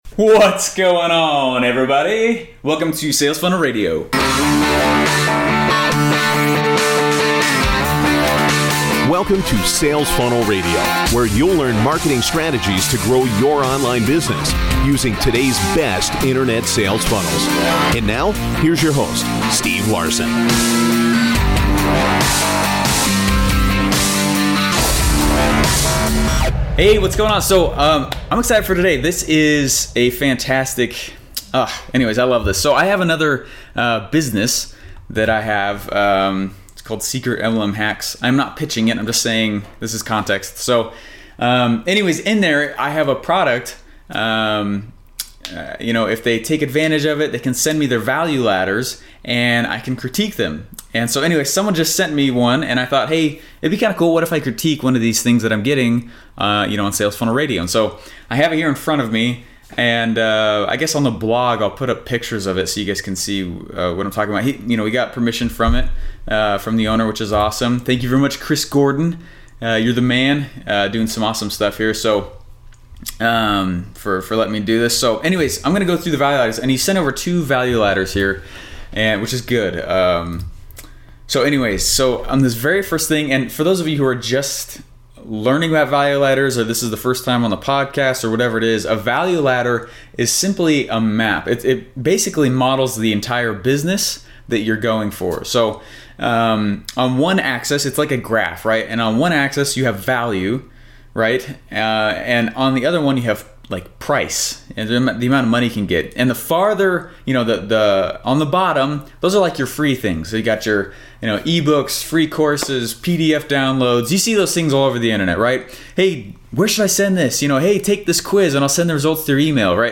Sales Funnel Radio - SRF 18: Live Critique Of A Listeners Value Ladder